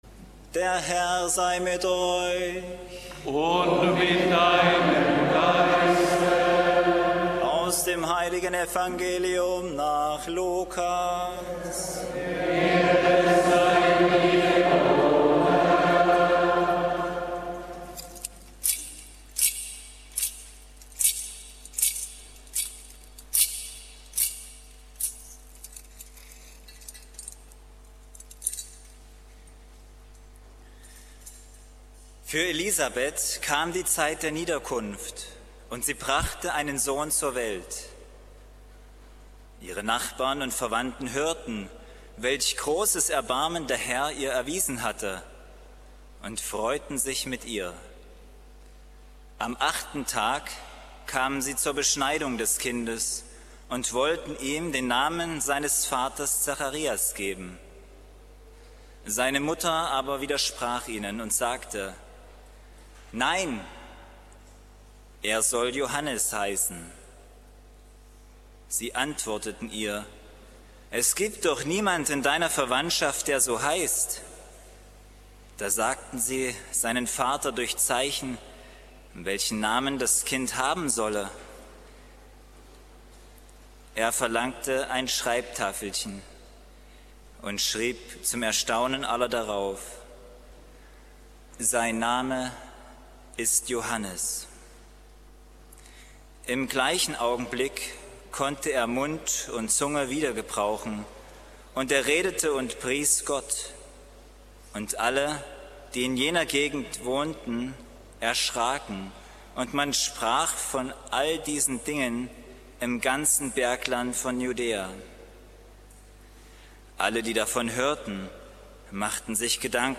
31. SNS Wr. Neustadt (Neukloster) am 24. Juni 2017 mit Kardinal Schönborn – Evangelium und Predigt (Danke an Radio Maria für die Übertragung und Aufnahme)